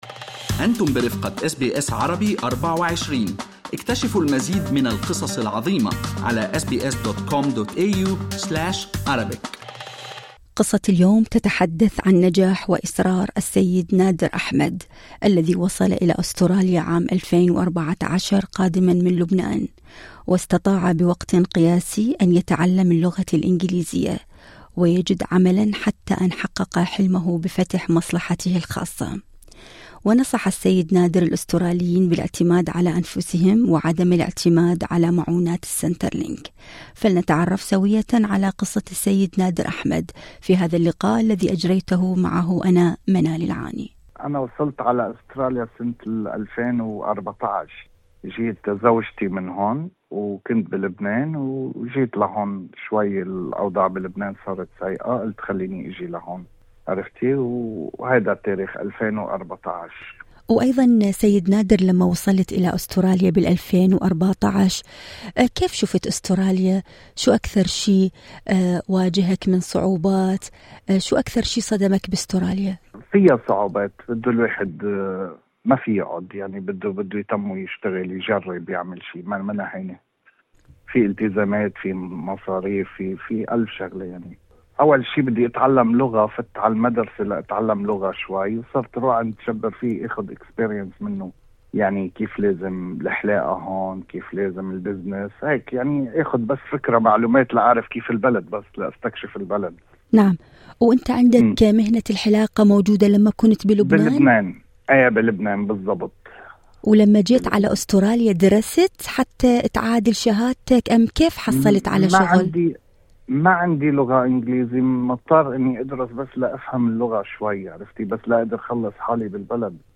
التفاصيل في المقابلة الصوتية اعلاه استمعوا لبرنامج "أستراليا اليوم" من الاثنين إلى الجمعة من الساعة الثالثة بعد الظهر إلى السادسة مساءً بتوقيت الساحل الشرقي لأستراليا عبر الراديو الرقمي وتطبيق Radio SBS المتاح مجاناً على أبل وأندرويد.